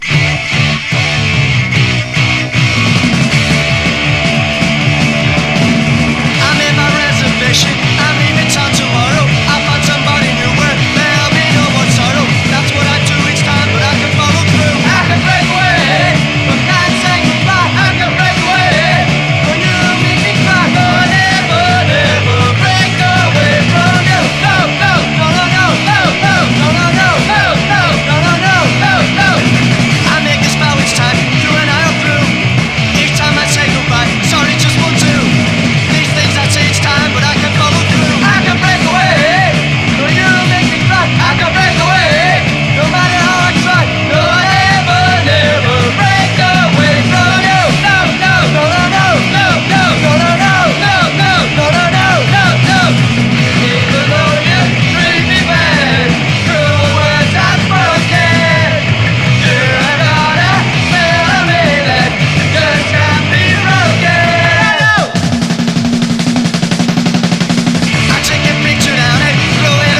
ROCK / PUNK / 80'S～